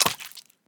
sounds / mob / dolphin / eat1.ogg
eat1.ogg